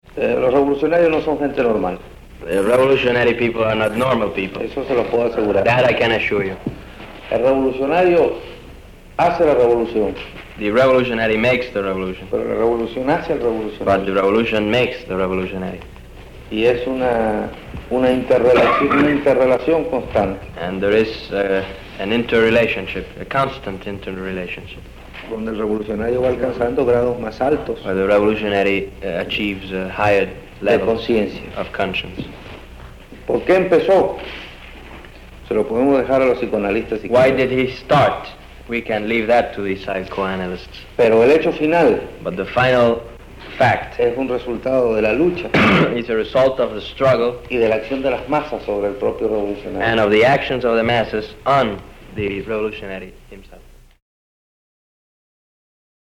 Che Guevara is asked many questions by American journalists. Translated from Spanish on the spot. Questions range from bureaucracy, Cuban propaganda, the Black struggle in America, Puerto Rico.